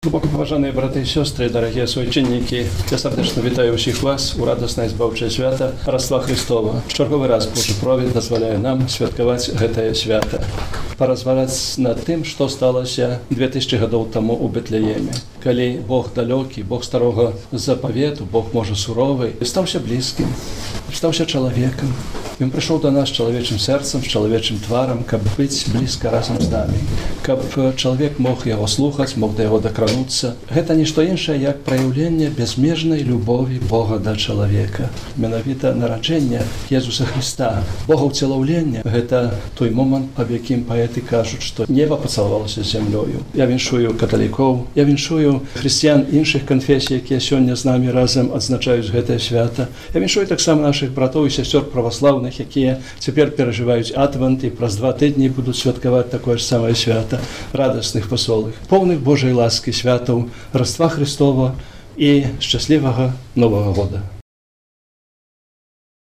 Рождественскую службу в костеле Святого Архангела Михаила в Ивенце провел Митрополит Минско-Могилевский архиепископ Тадеуш Кондрусевич. Его поздравления - в нашем эфире:
Архиепископ Тадеуш Кондрусевич